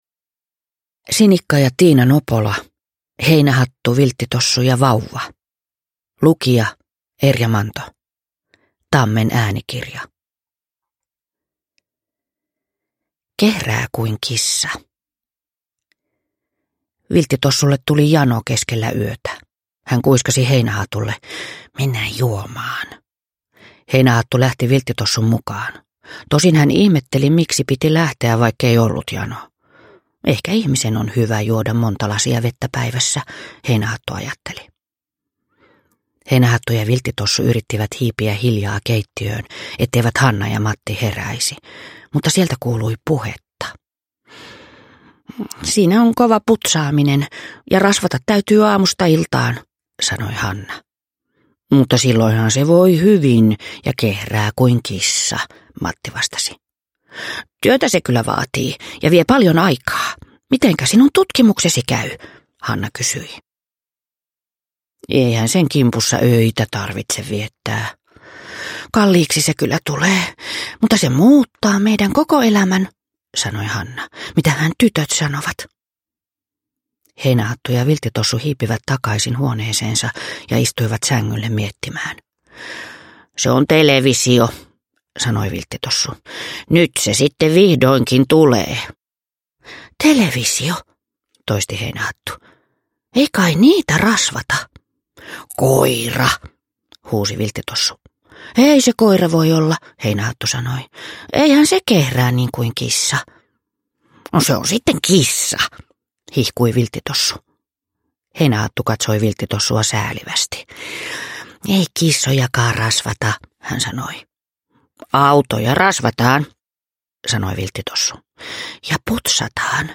Heinähattu, Vilttitossu ja vauva – Ljudbok – Laddas ner